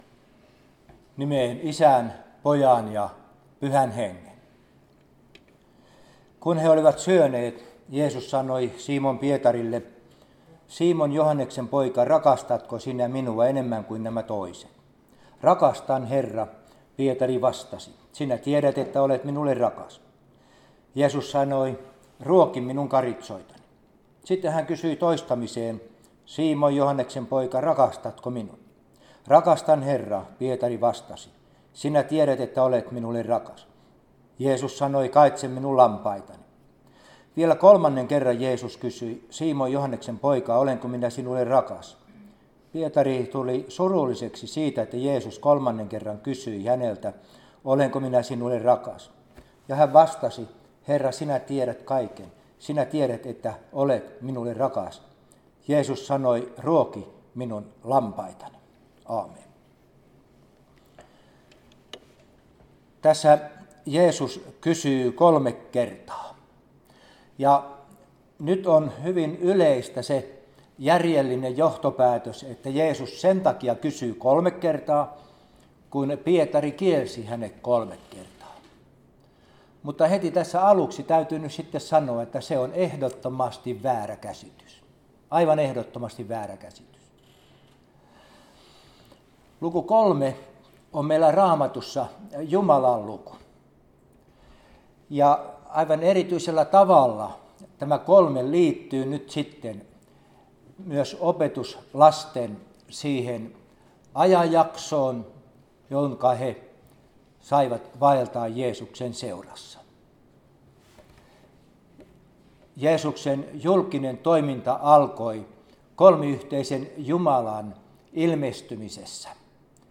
Hengellinen ilta 2 osa Jalasjärvi
Hirvijärven kuoro